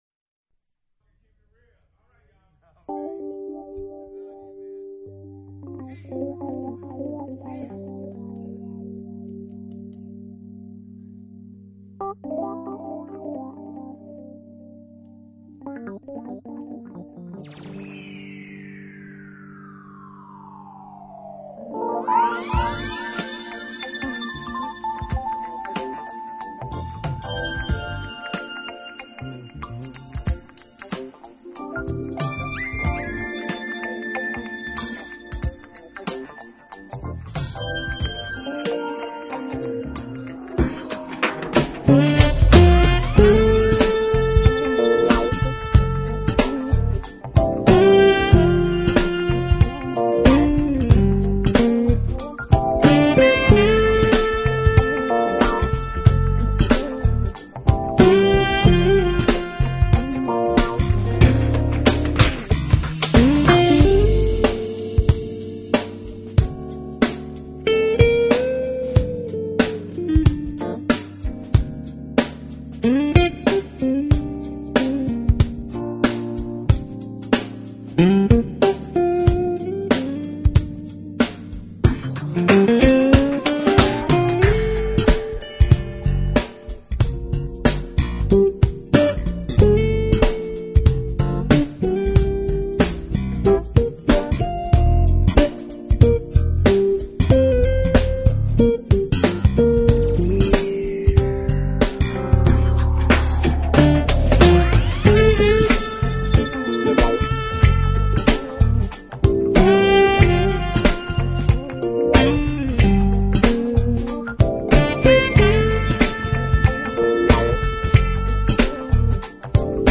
【爵士吉他】